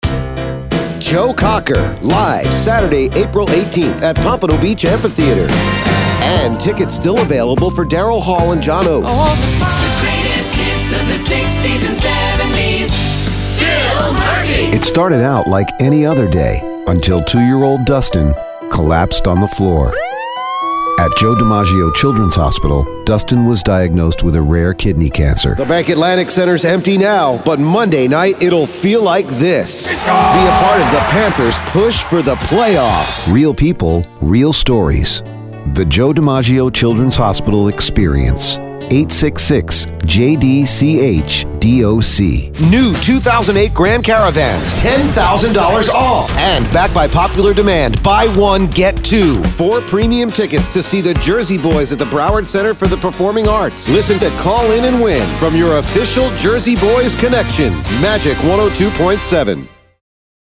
COMMERCIALS/JINGLES/PROMOS
Production/Commercial/Promo Quick Demo